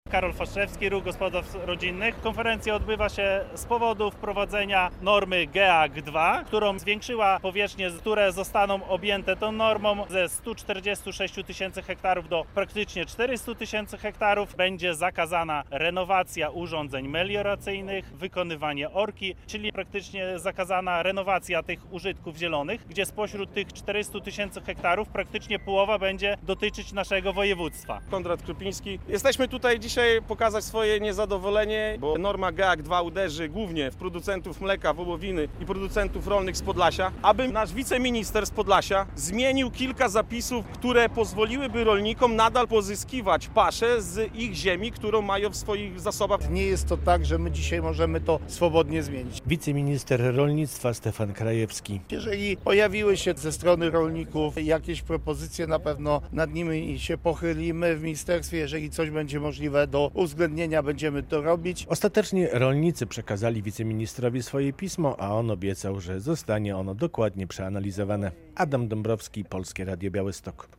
Radio Białystok | Wiadomości | Wiadomości - Unijna norma uderzy w rolnictwo?
Wzięło w niej udział kilkunastu rolników.